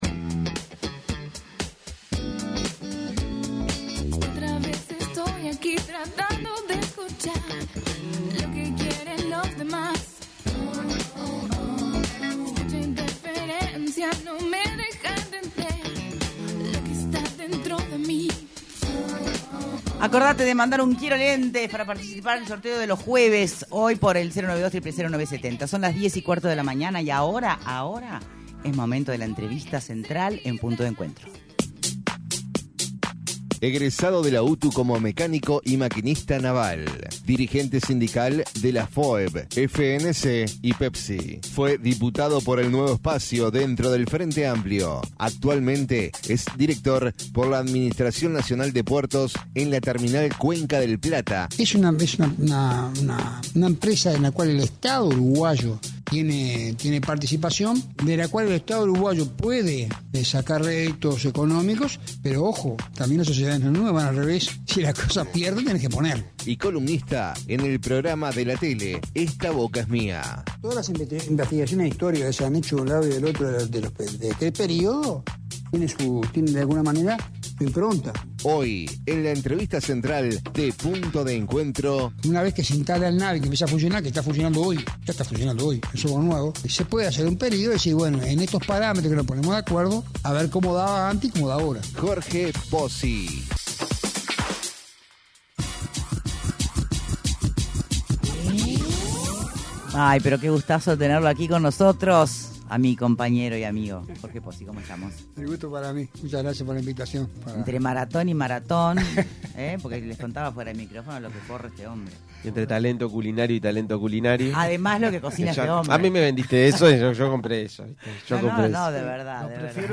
En entrevista en Punto de Encuentro, el director en Terminal Cuenca del Plata por la Administración Nacional de Puertos del Frente Amplio, Jorge Pozzi se refirió a la crispación del debate político actual y señaló que no le hace bien ni al gobierno ni a la oposición.